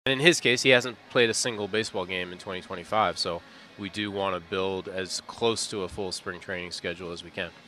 Cherington says it will still take a while for Horwitz to get ready.